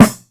• Short Steel Snare Drum Sample A Key 72.wav
Royality free acoustic snare tuned to the A note. Loudest frequency: 1269Hz
short-steel-snare-drum-sample-a-key-72-NFn.wav